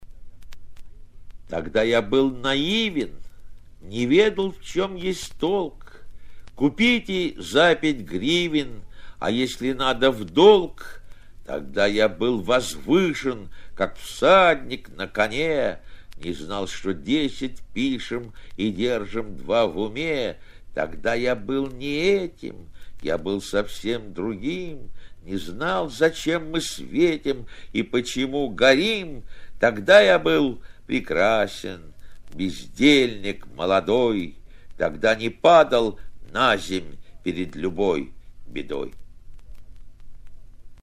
1. «Давид Самойлов – Тогда я был наивен… (читает автор)» /